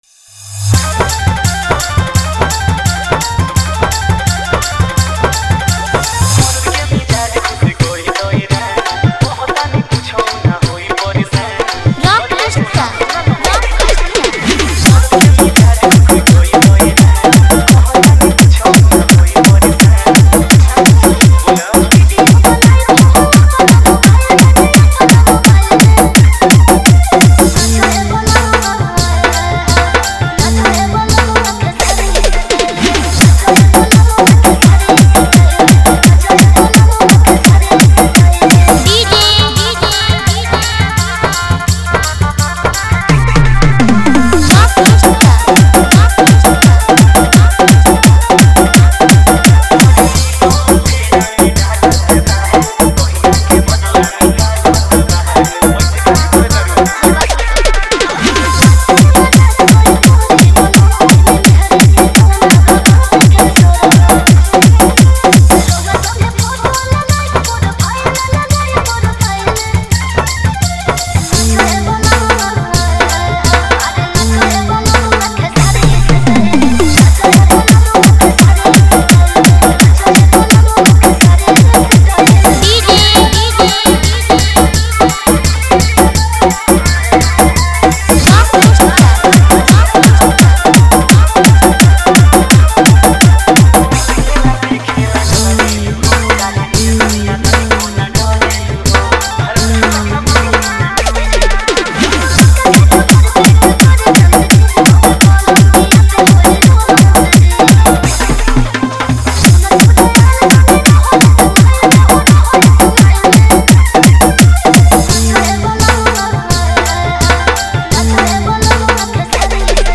Category:  Bhojpuri Dj Remix